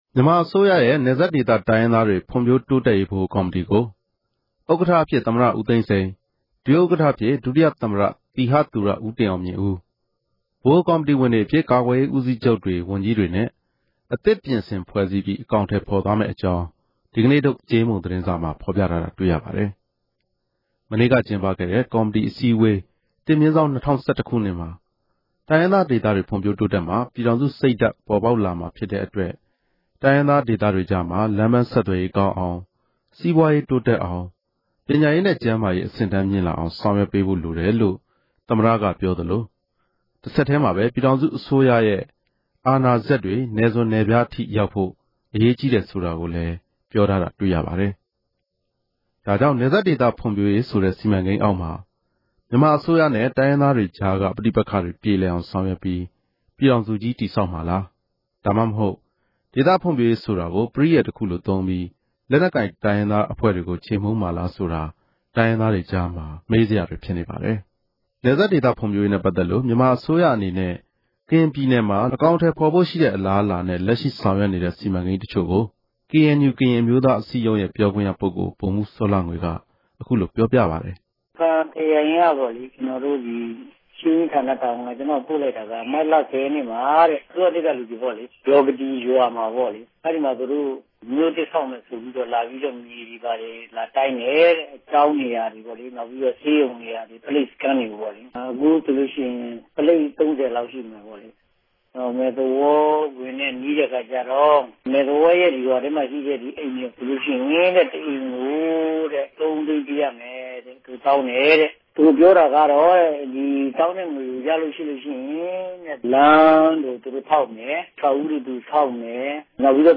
စုစည်းတင်ပြချက်။